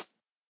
Minecraft Version Minecraft Version snapshot Latest Release | Latest Snapshot snapshot / assets / minecraft / sounds / mob / rabbit / hop3.ogg Compare With Compare With Latest Release | Latest Snapshot
hop3.ogg